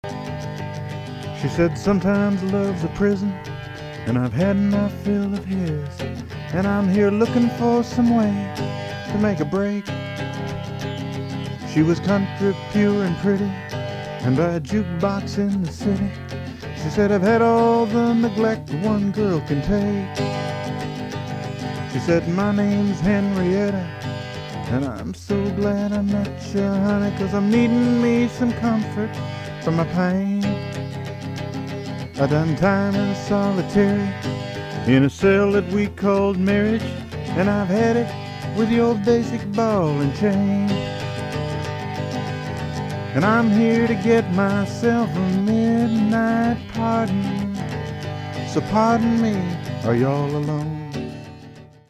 Our client’s “before” rough version: